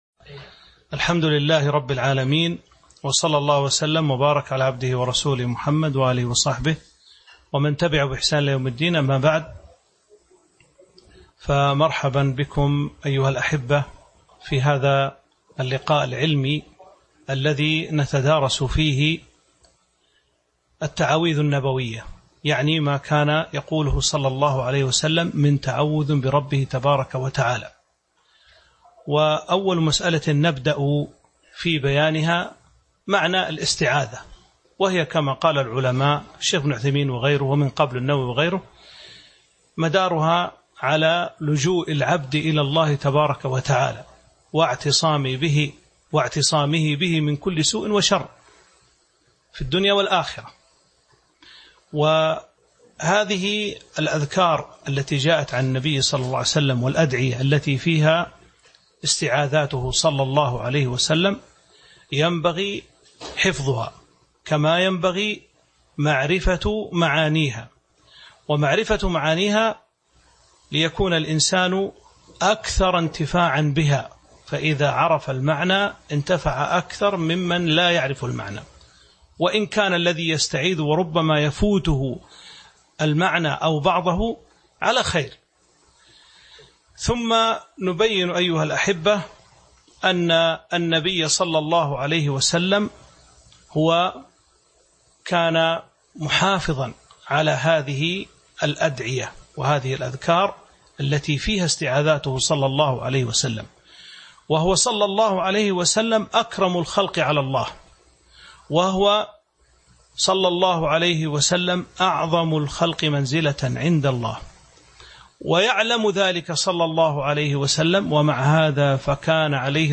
تاريخ النشر ٤ صفر ١٤٤٢ هـ المكان: المسجد النبوي الشيخ